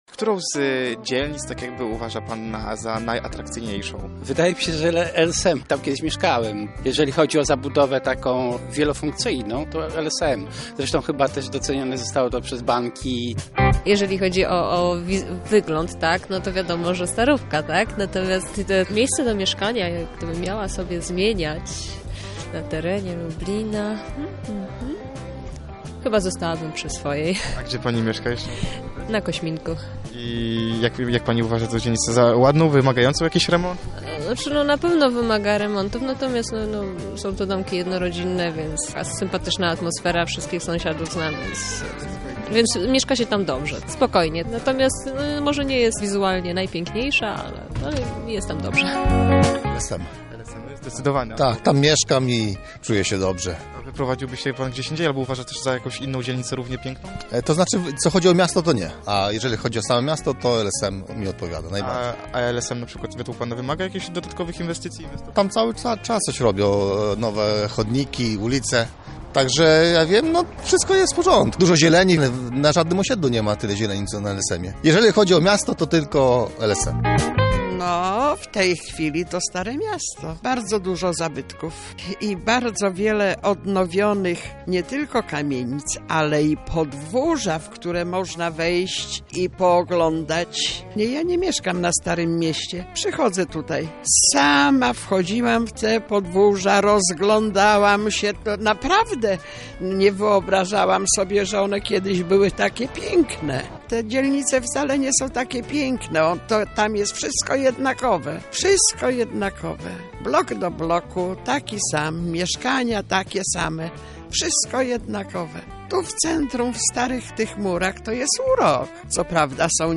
Zapytaliśmy mieszkańców Lublina, czy są zadowoleni ze swojej dzielnicy i gdzie chcieliby mieszkać.